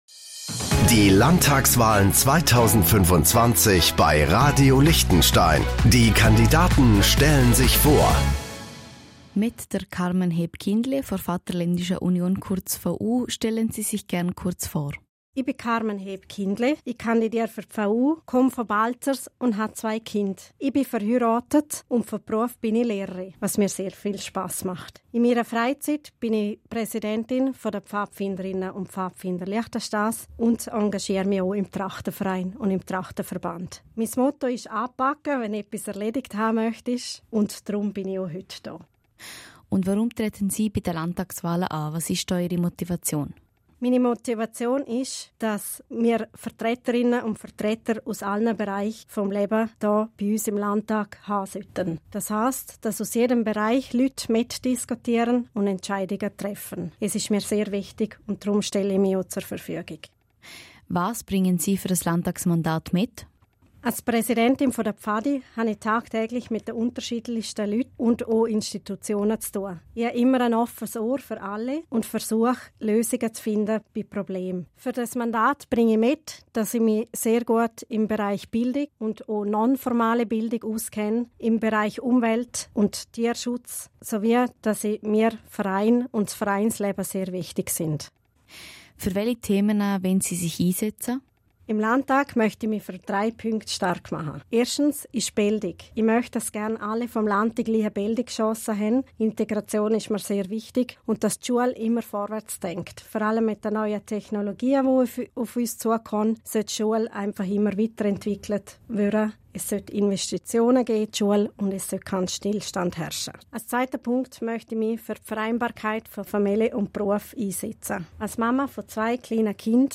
Landtagskandidatin